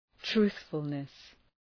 Προφορά
{‘tru:ɵfəlnıs}